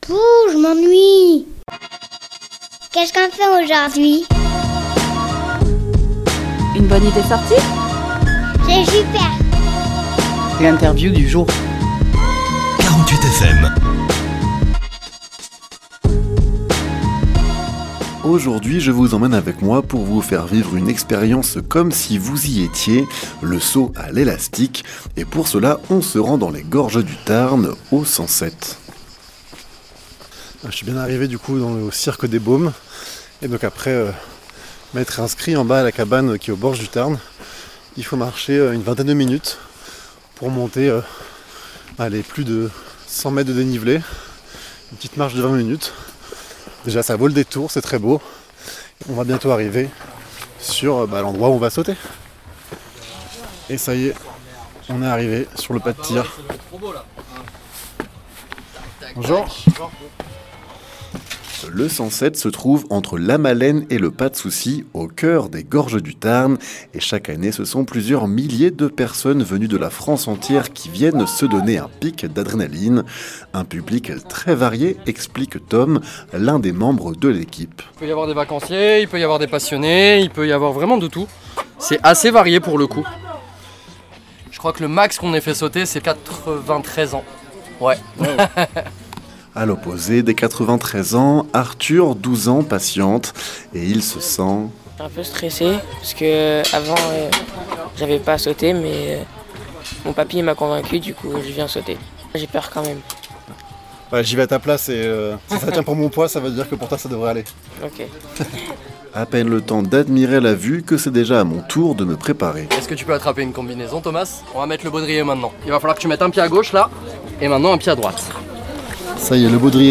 Embarquez pour un reportage comme si vous y étiez.
Reportage